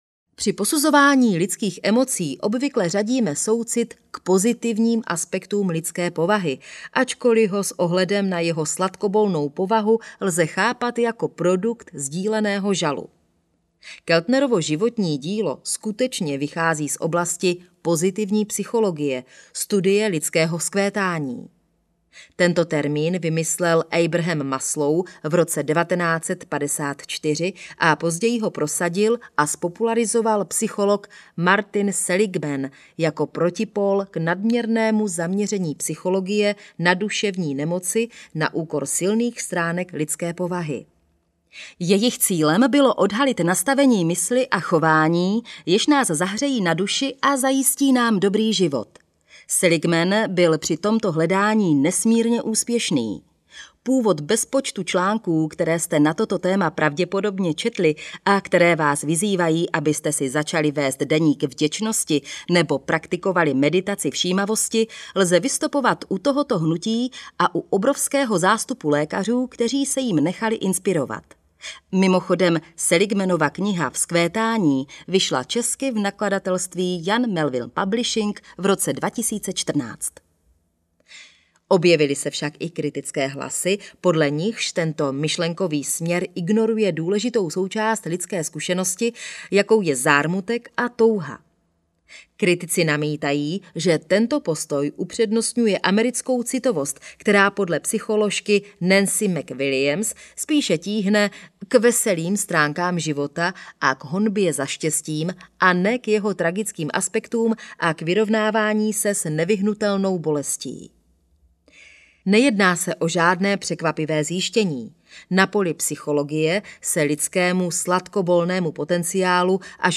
Audiokniha Sladkobol - Susan Cain | ProgresGuru